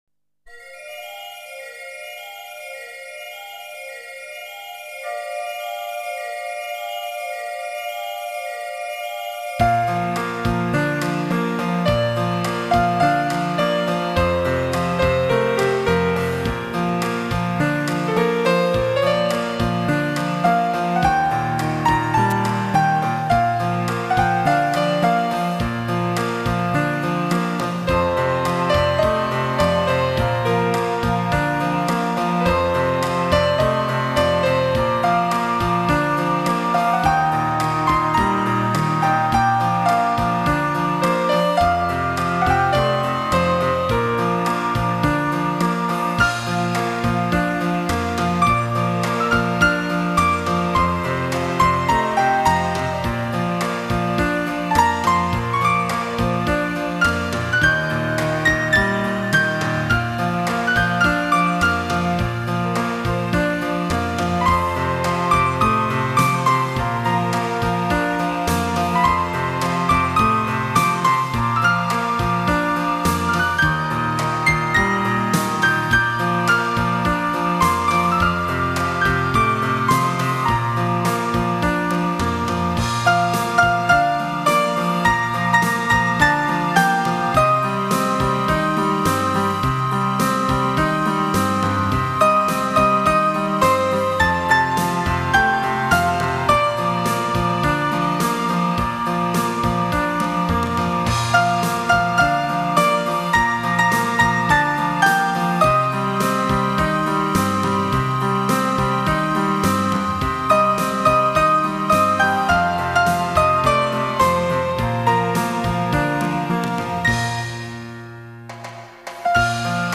纯音
让伤感在琴声中融化。